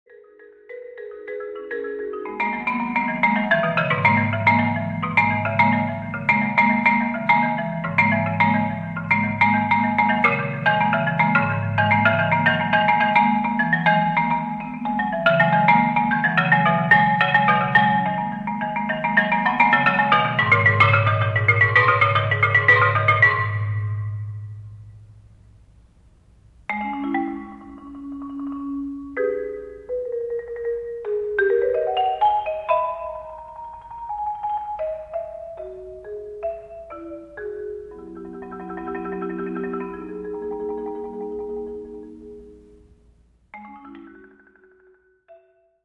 - for Solo Marimba
[Four mallets - 4 1/3 octave marimba]. college/professional.